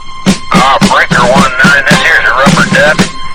PLAY rubberduck
rubberduck.mp3